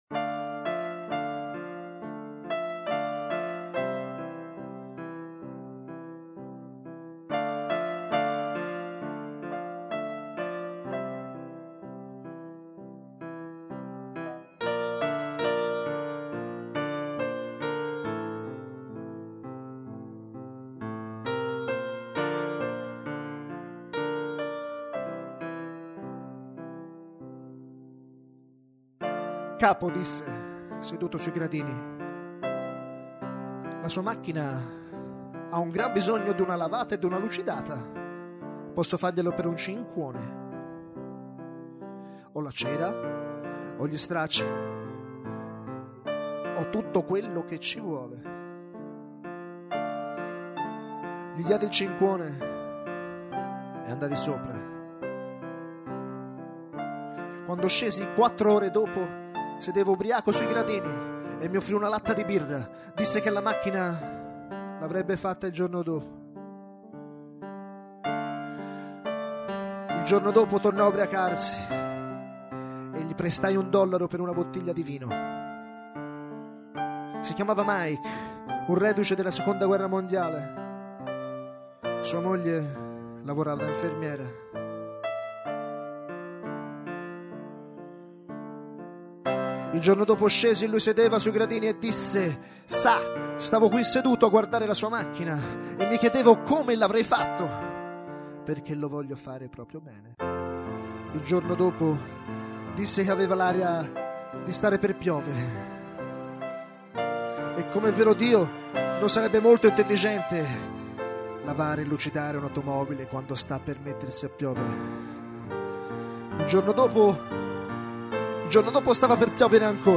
Eccomi quindi in una esecuzione pianistica improvvisata, mentre recito i versi di quello che secondo me e' stato il piu' grande poeta moderno.